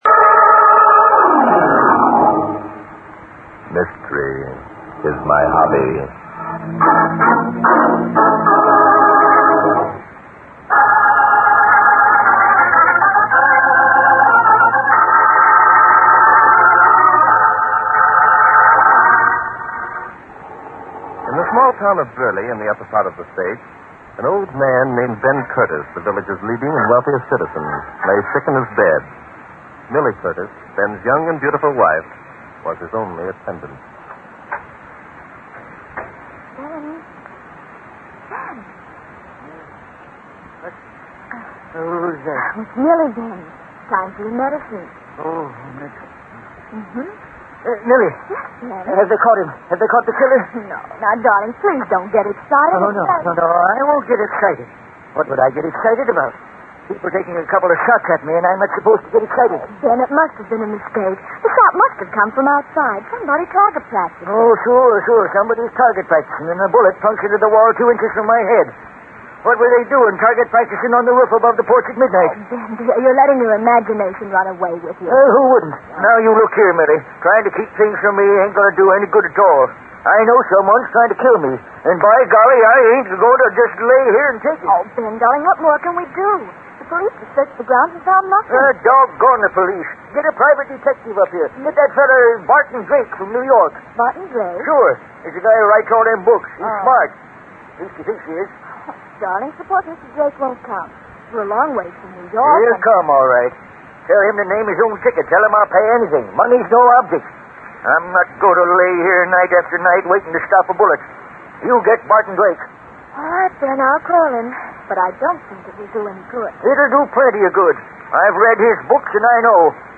Starring Glen Langan as the lead character Barton Drake, Mystery Is My Hobby is a detective series that aired on Mutual Radio from 1947 to 1948. In the series, Drake was writer who loves to solve crimes and mysteries, together with his sidekick Noah Danton, a police officer.